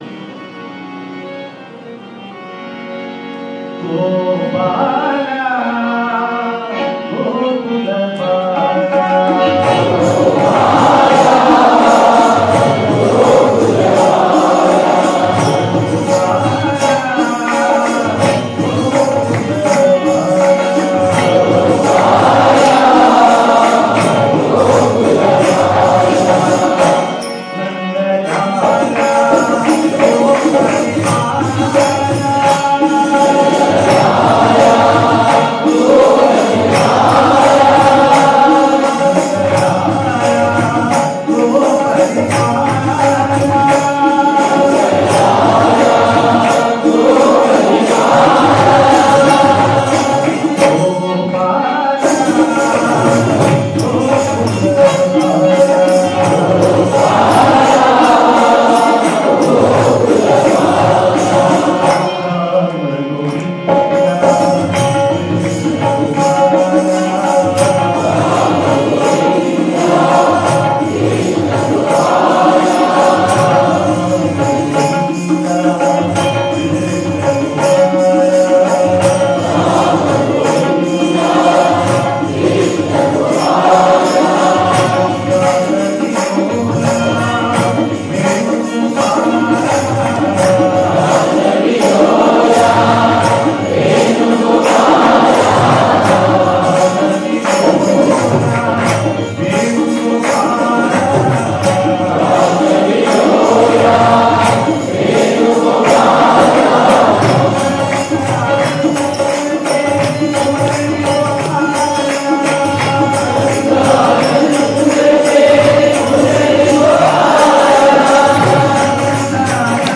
1. Devotional Songs
Pantuvarali / Puriya Dhanashri
8 Beat / Keherwa / Adi
4 Pancham / F
1 Pancham / C